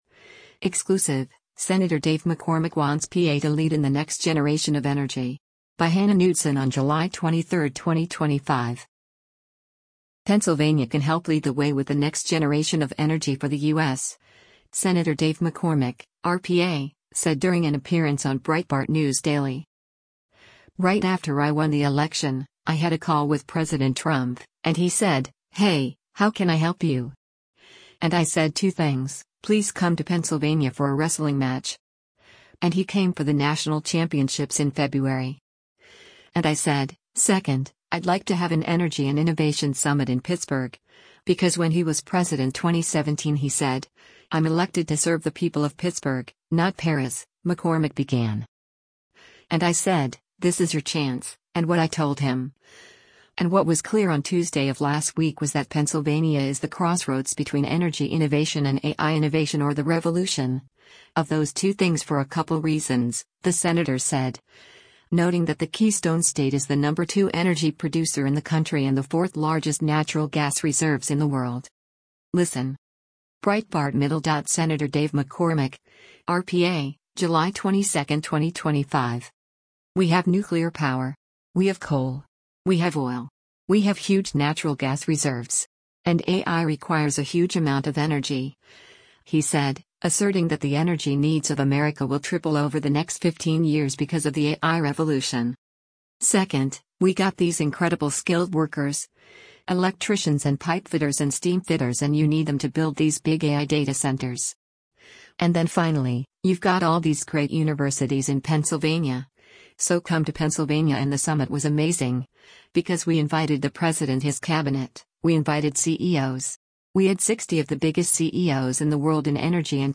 Pennsylvania can help lead the way with the next generation of energy for the U.S., Sen. Dave McCormick (R-PA) said during an appearance on Breitbart News Daily.
Breitbart News Daily airs on SiriusXM Patriot 125 from 6:00 a.m. to 9:00 a.m. Eastern.